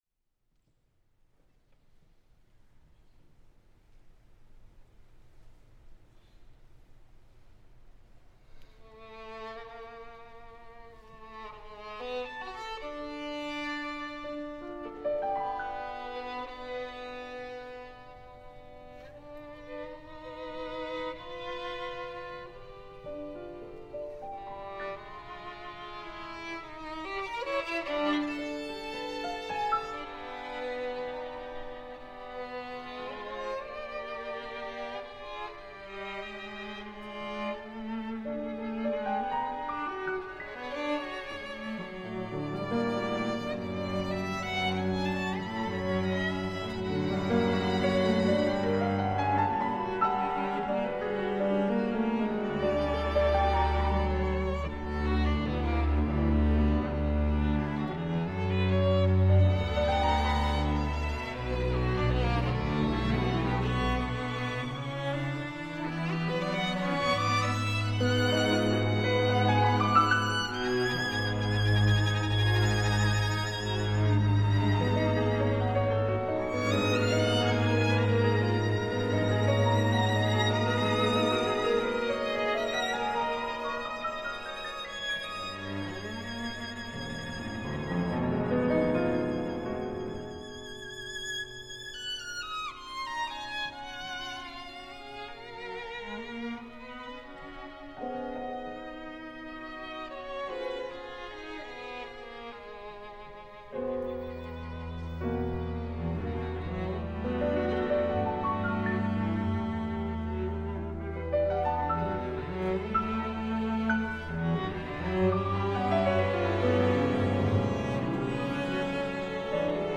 Performance at the Walt Disney Hall